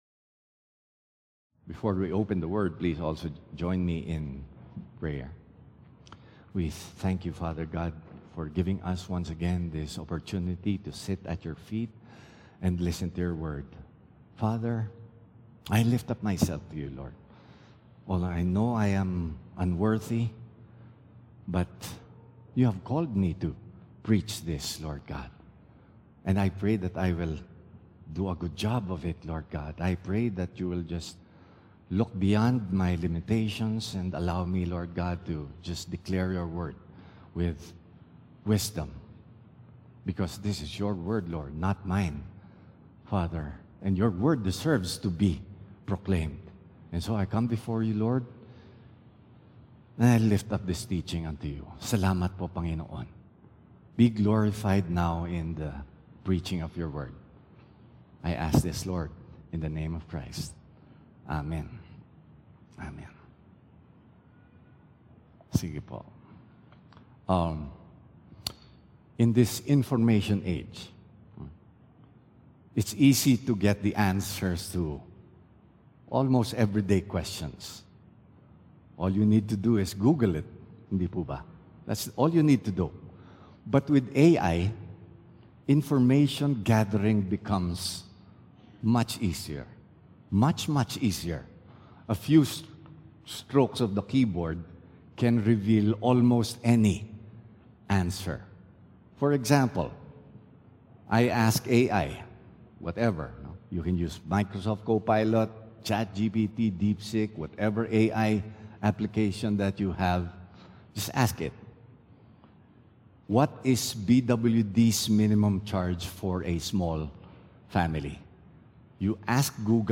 WATCH AND BE BLESSED Where Can Wisdom Be Found? 1 Videos April 27, 2025 | 9 A.M Service Where Can Wisdom Be Found? | Job 28:12-28 Information Information No Slides available for this Sermon.